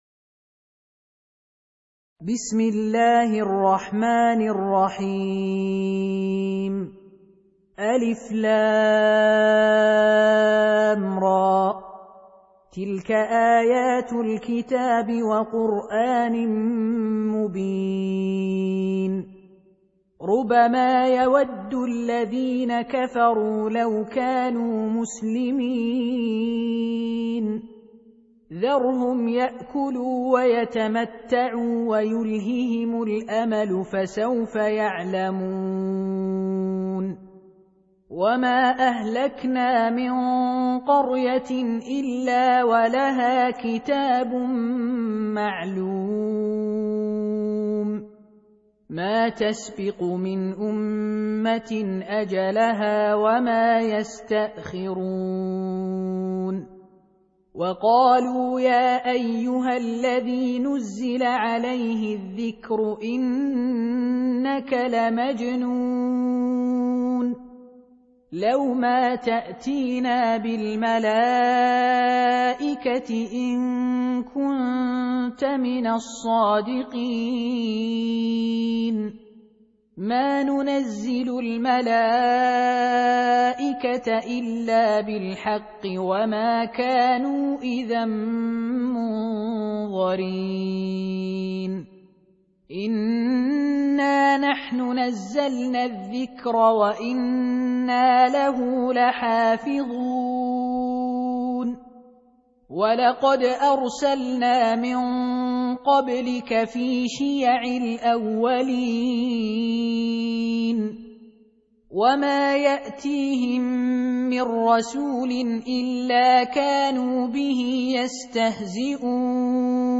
15. Surah Al-Hijr سورة الحجر Audio Quran Tarteel Recitation
Surah Repeating تكرار السورة Download Surah حمّل السورة Reciting Murattalah Audio for 15.